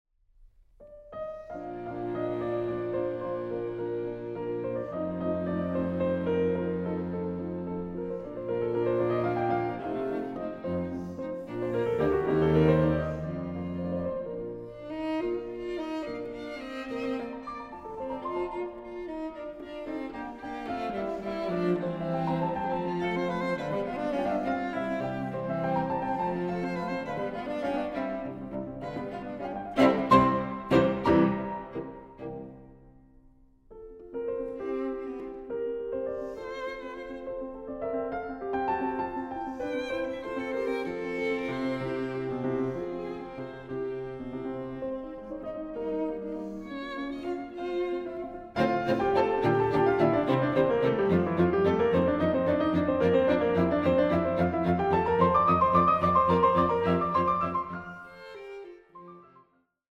Recording: Festeburgkirche Frankfurt, 2024